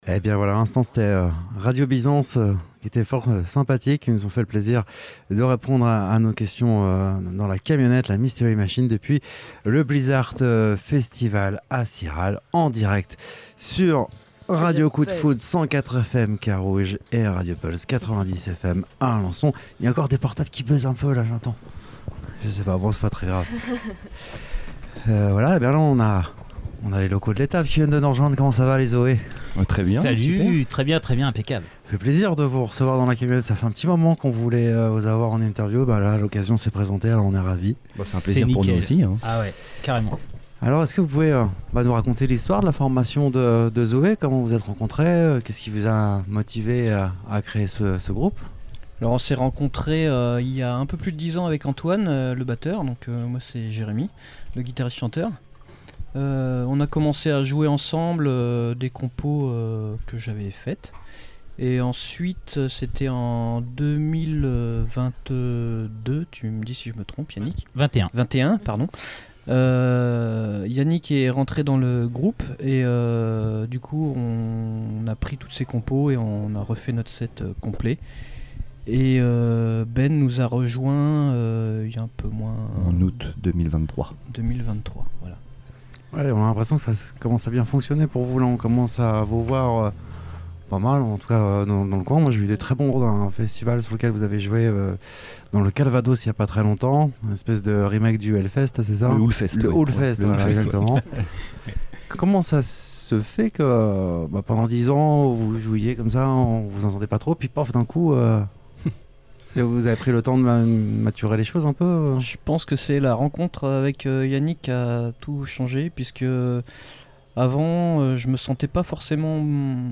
Interviews RCDF Le groupe [ZOË] dans la Mystery Machine !